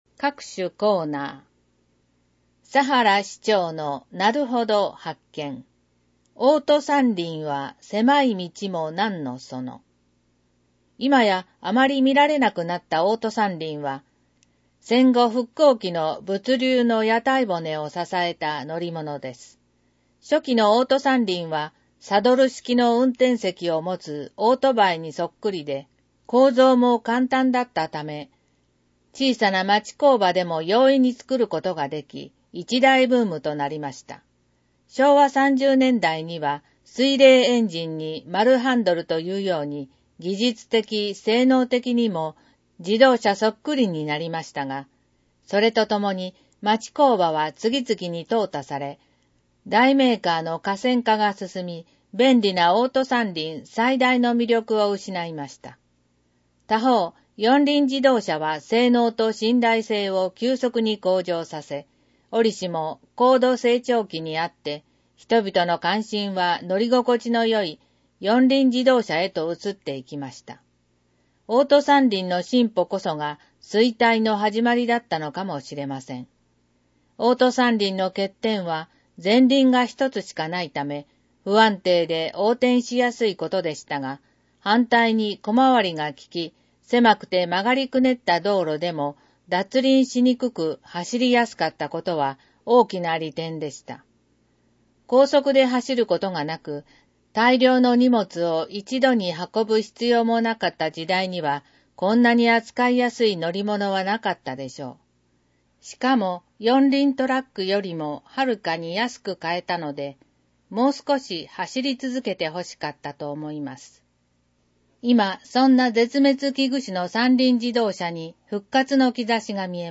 • 「広報とよはし」から一部の記事を音声でご案内しています。視覚障害者向けに一部読み替えています。
（音声ファイルは『音訳グループぴっち』提供）